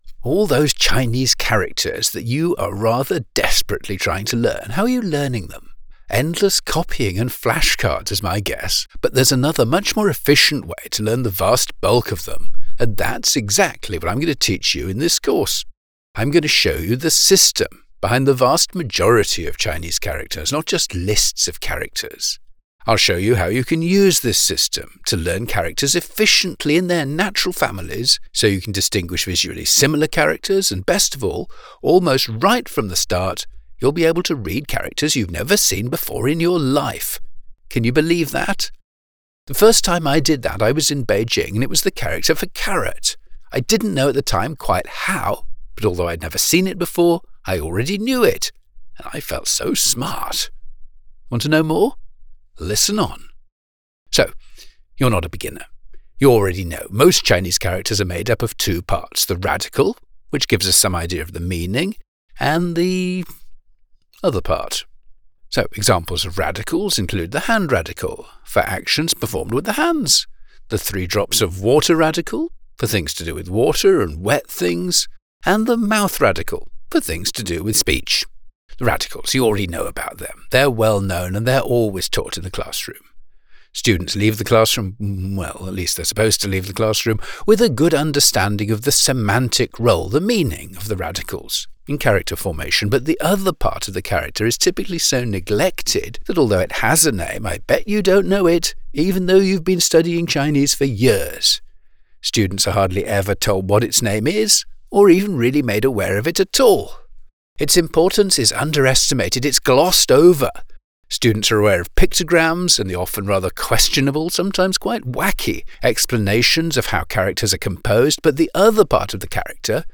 British E-Learning Narrator:
Thanks to this background, I am extremely capable of delivering e-learning voiceovers in an appropriately lively, friendly and engaging manner.